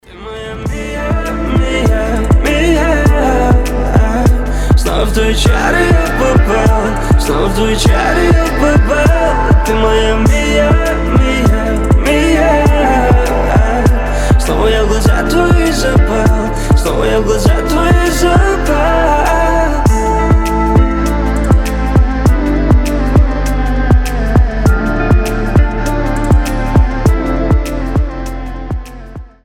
красивые
лирика
дуэт